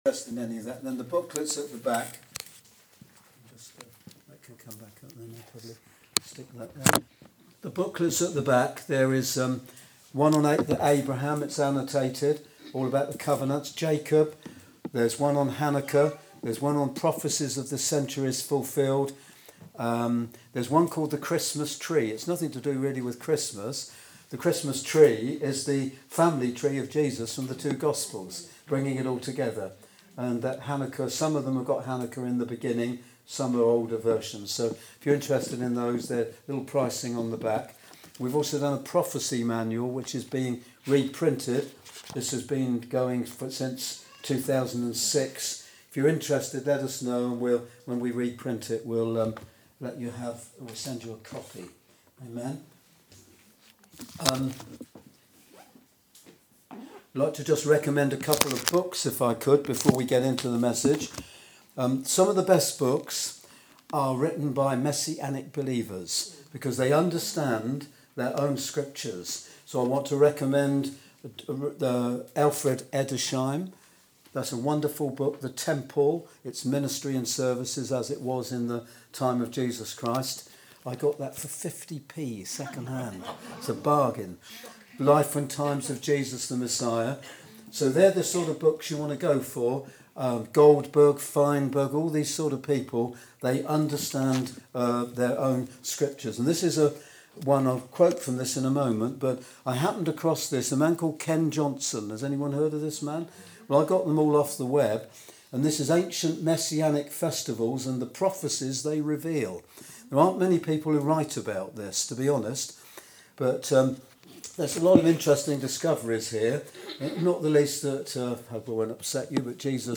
Tabernacles 2018 – messages